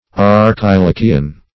Meaning of archilochian. archilochian synonyms, pronunciation, spelling and more from Free Dictionary.
Search Result for " archilochian" : The Collaborative International Dictionary of English v.0.48: Archilochian \Ar`chi*lo"chi*an\, a. [L. Archilochius.] Of or pertaining to the satiric Greek poet Archilochus; as, Archilochian meter.